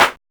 LINN CLAP.wav